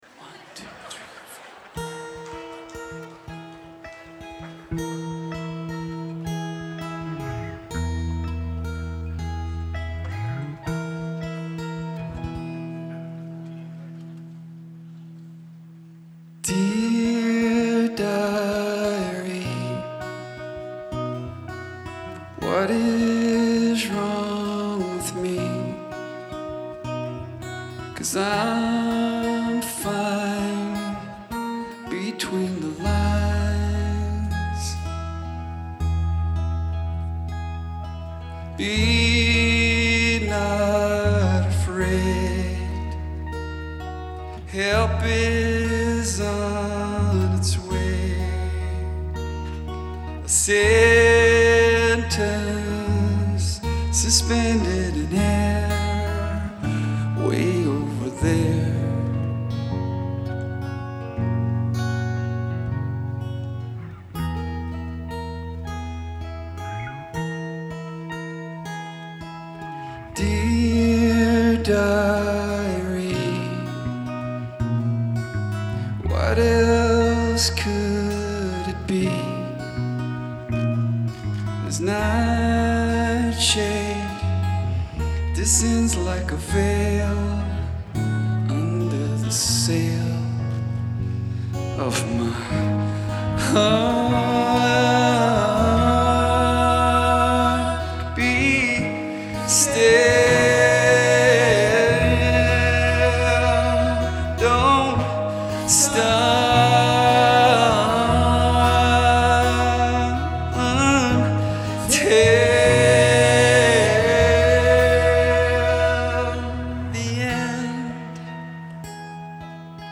Genre : Alternatif et Indé